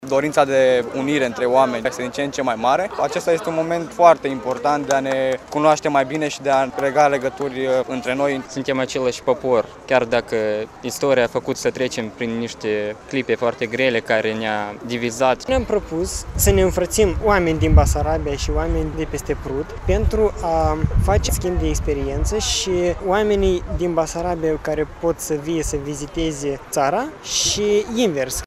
Aproape 100 de tineri din România şi Republica Moldova s-au înfrăţit simbolic ieri, în cadrul proiectului „Basarabia-acasă”. Manifestarea a fost organizată de Platforma Unionistă Acţiunea 2012, la Kilometrul ZERO al Bucureştiului.
stiri-9-iul-vox-infratire.mp3